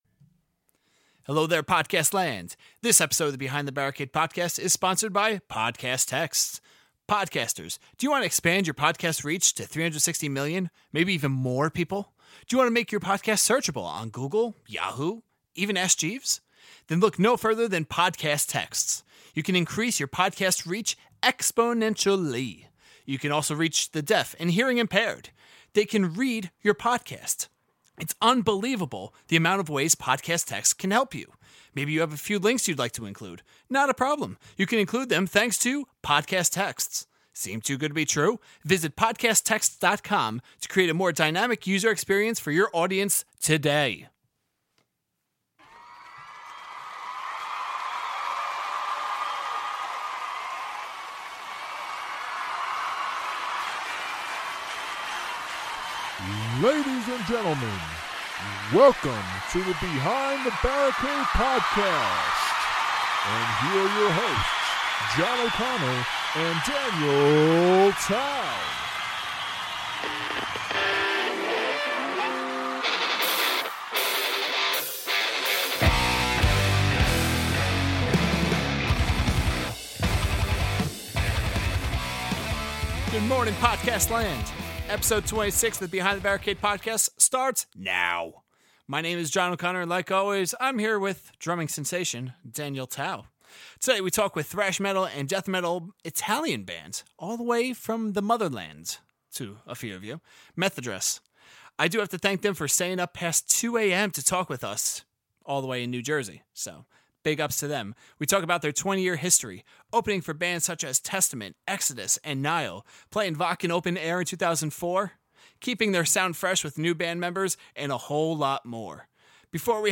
Pre-Interview Song: Subversion Post-Interview Song: You Got It